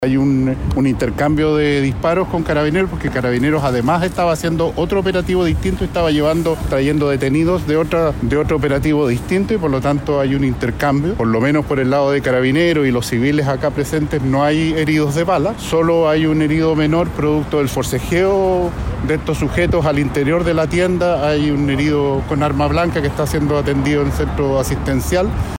Tras lo ocurrido, el delegado presidencial de Valparaíso, Yanino Riquelme, indicó que no hubo lesionados en el intercambio de disparos, pero que hay un herido con arma blanca que debió ser atendido en un centro asistencial.